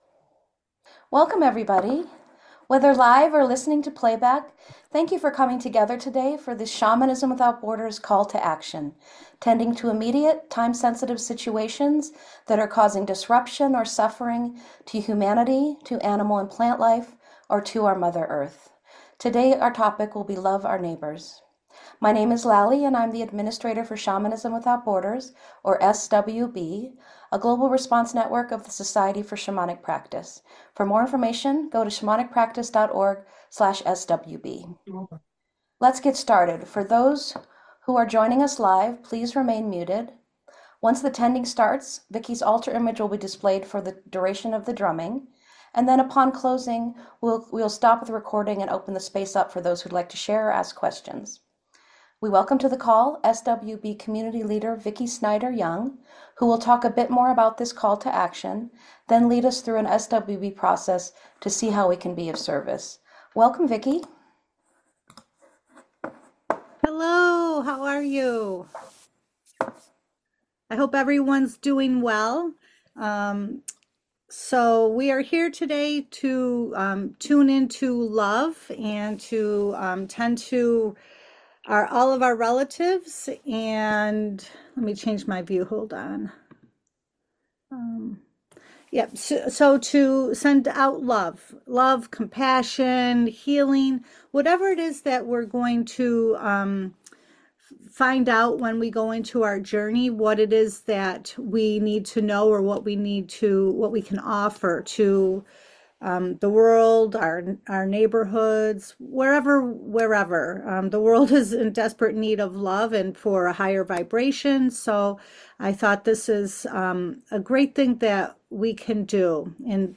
You are welcome to rattle or drum along during the shamanic journey process.